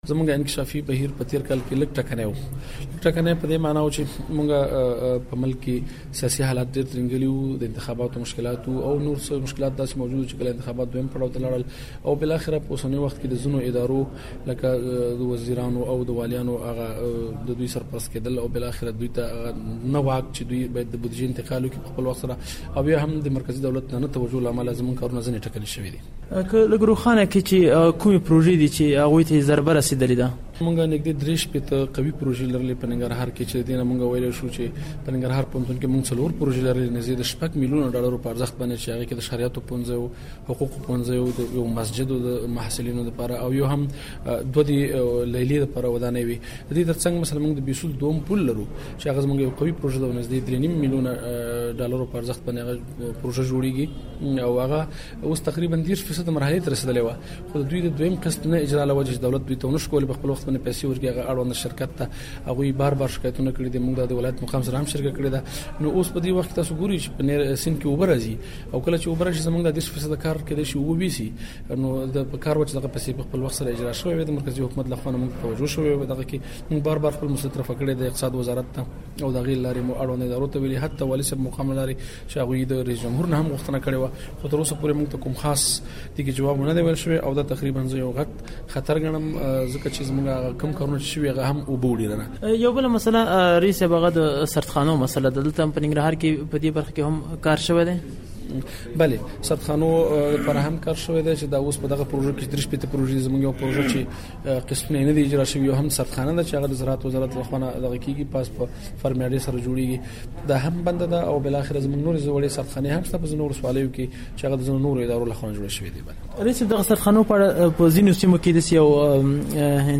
د ننګرهار د اقتصاد له رییس سمیع اله نصرت سره مرکه